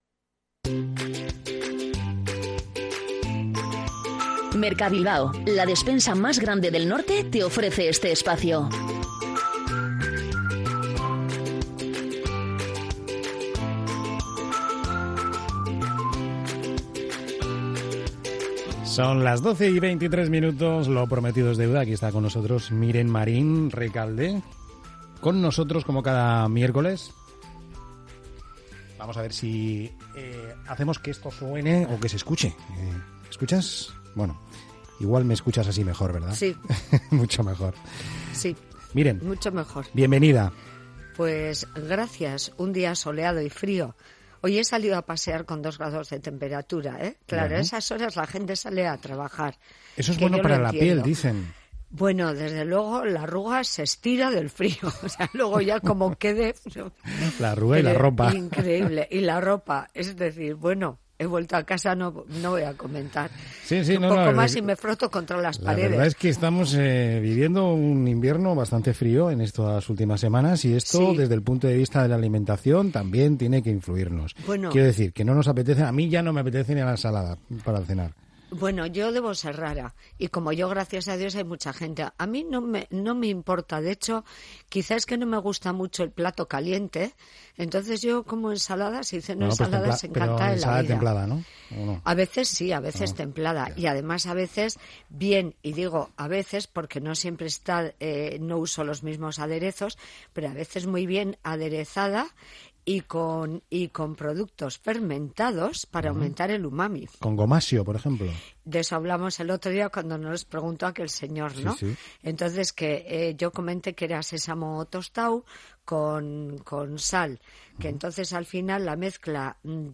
Tomando el pulso a la actualidad en el mediodía más dinámico de la radio.
Voces cercanas que conectan de buena mañana con lo que está pasando a nuestro alrededor.